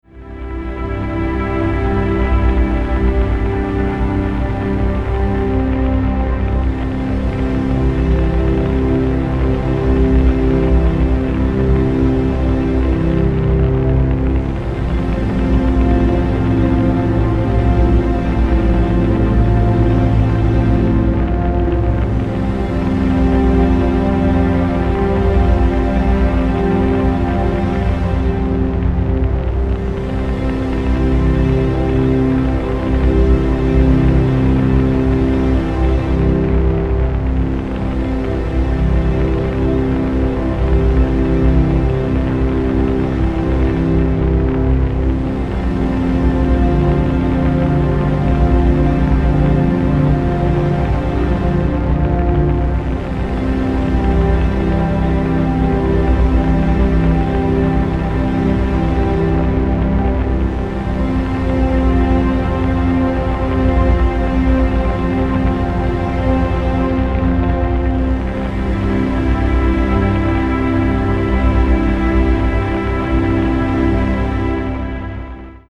AMBIENT/DOWNTEMPO